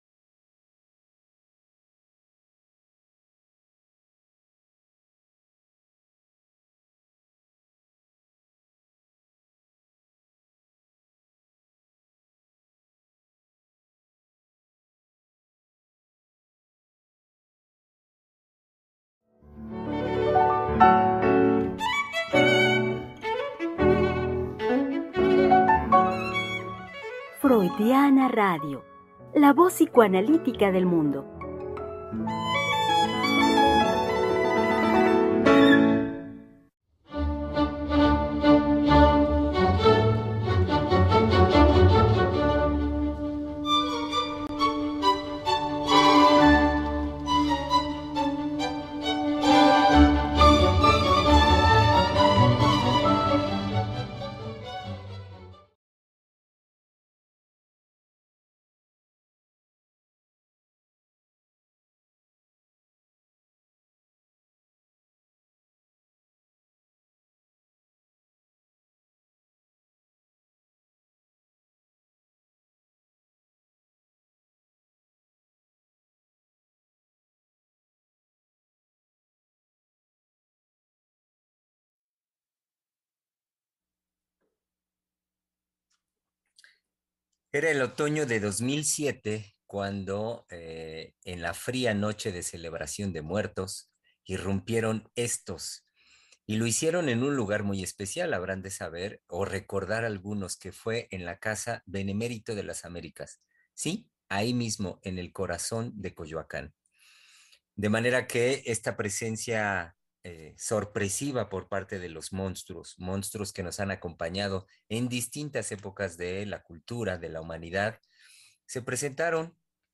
Es miércoles de “Volver a pensar” en Freudiana Radio conversaremos con las psicoanalistas